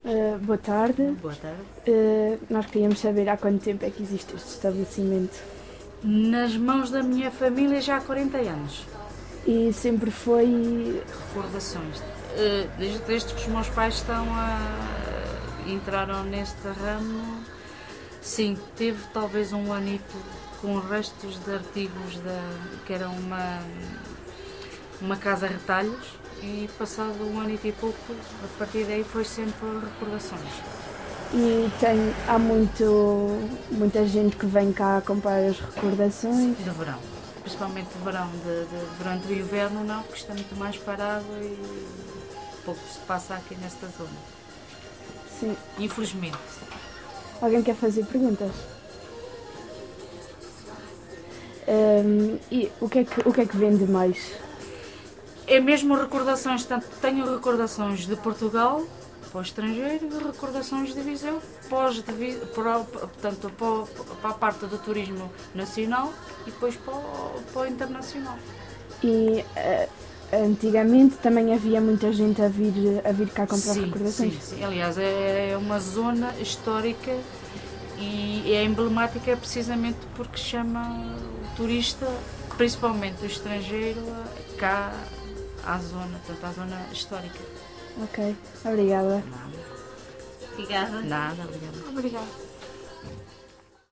Gravado por no dia 13 Abril 2011 às 15h39 com um gravador digital Edirol R44 e um par de microfones de lapela Audio-Technica AT899.
Inquérito Etnográfico, Inquérito Oral